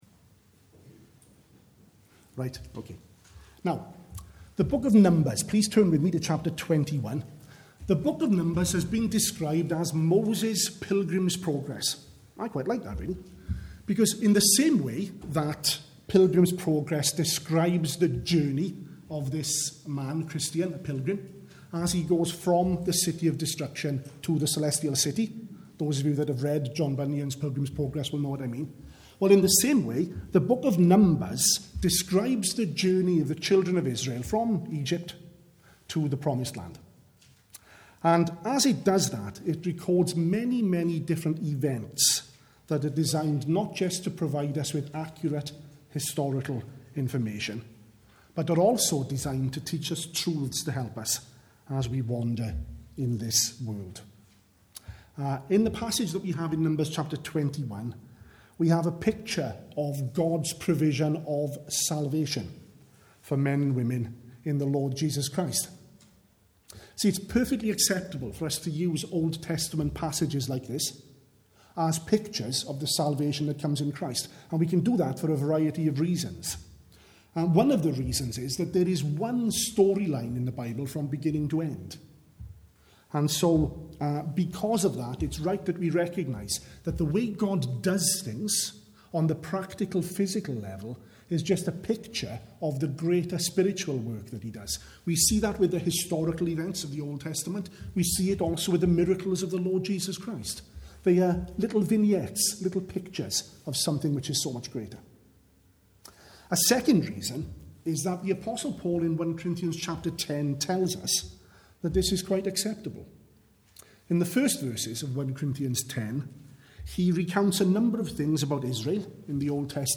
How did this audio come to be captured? at the morning service